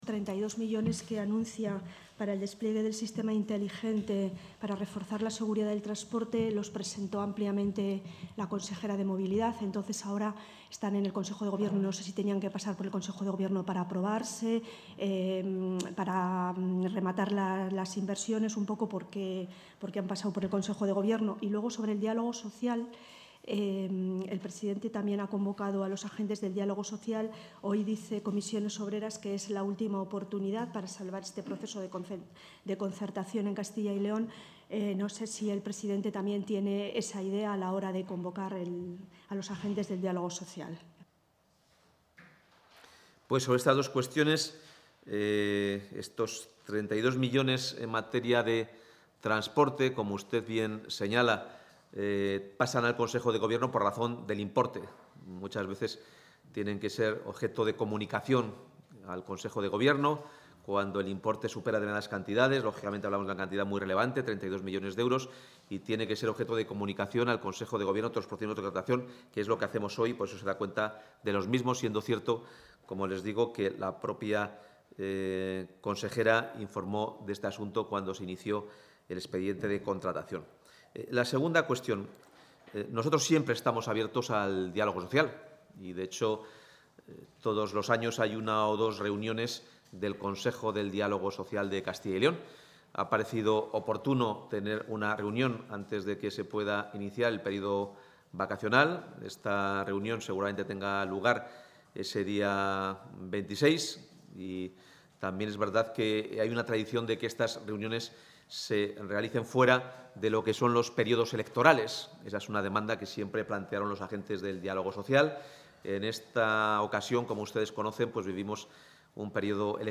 Intervención del portavoz de la Junta.